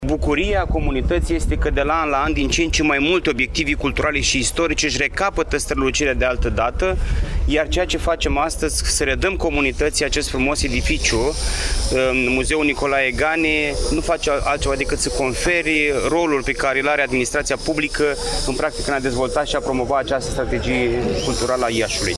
Cu acest prilej, președintele Consiliului Județean Iași, Costel Alexe, a declarant că până la 1 septembrie va fi redată circuitului muzeal și Bojdeuca Ion Creangă: ”Bucuria comunității este că de la an la an, din ce în ce mai multe obiective culturale și istorice își recapătă strălucirea de altădată, iar ceea ce facem, astăzi, să redăm comunității acest frumos edificiu, Muzeul ”Nicolae Gane” nu face altceva decât să confere rolul pe care îl are administrația publică, practic, în a dezvolta și a promova această strategie culturală a Iașului.”